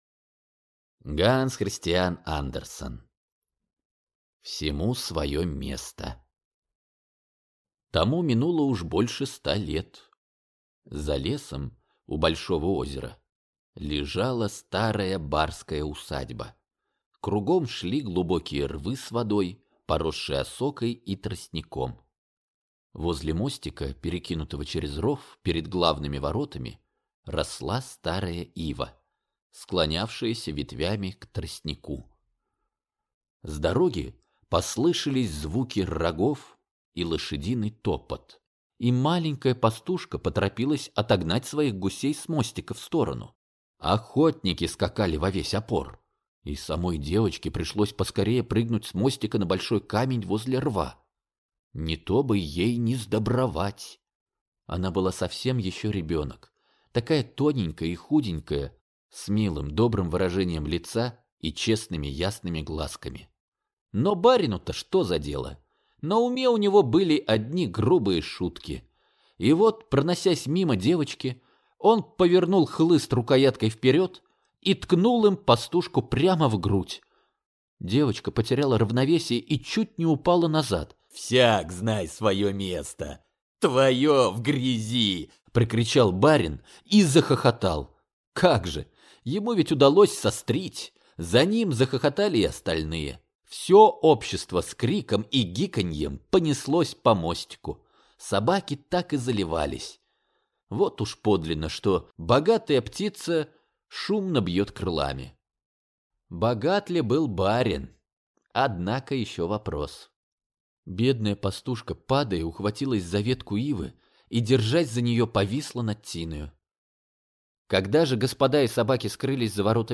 Аудиокнига Всему своё место | Библиотека аудиокниг